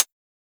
Index of /musicradar/ultimate-hihat-samples/Hits/ElectroHat C
UHH_ElectroHatC_Hit-06.wav